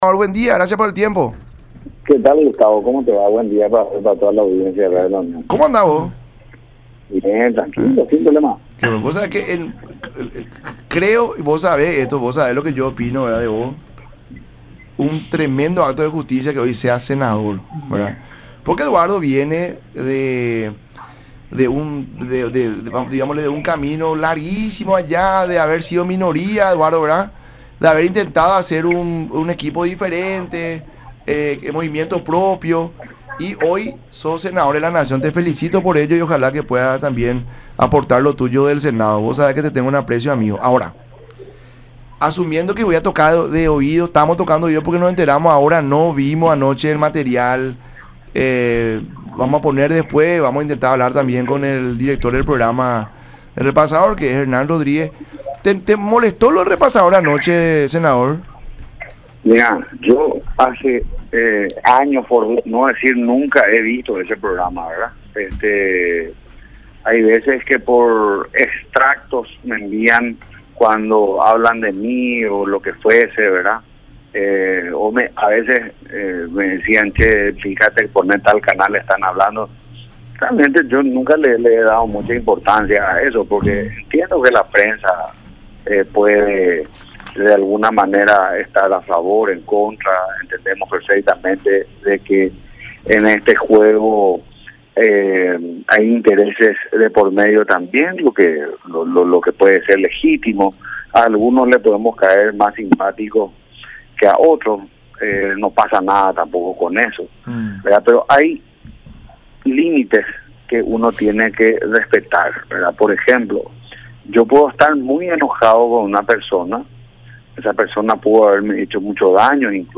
“Vamos a estudiar la posibilidad de tomar acciones, porque esto no se puede tolerar, el agravio es general”, dijo Nakayama en conversación con el programa “La Mañana de Unión” por Unión TV y radio La Unión.